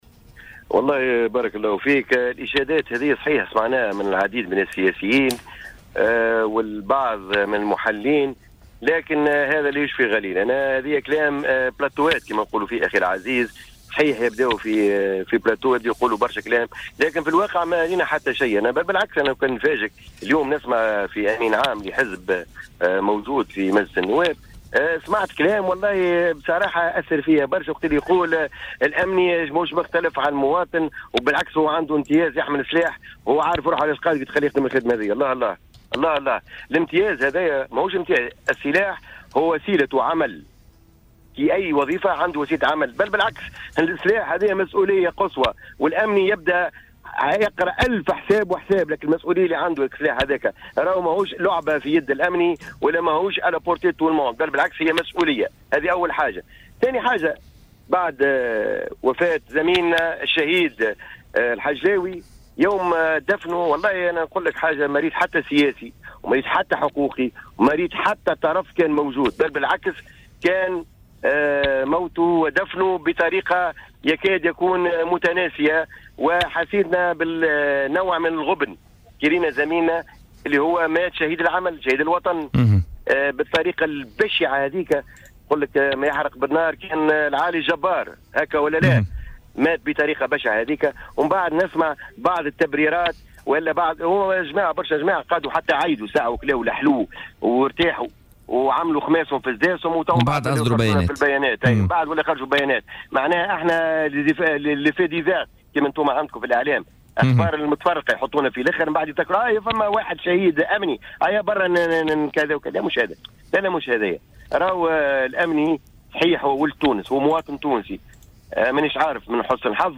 وقال في مداخلة له اليوم في برنامج "بوليتيكا"